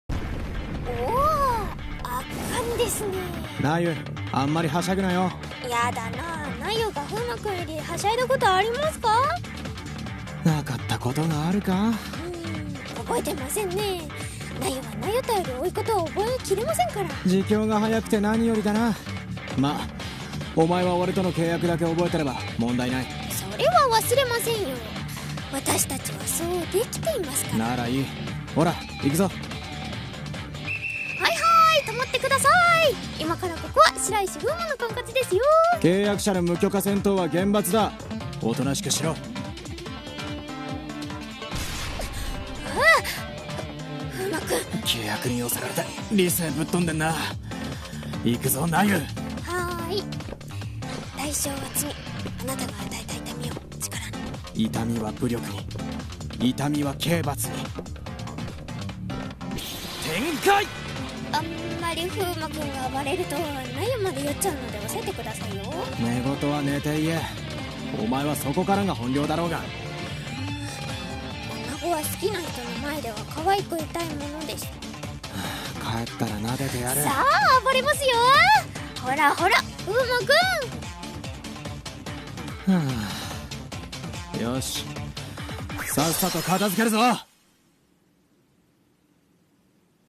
【二人声劇】制圧ノーライセンス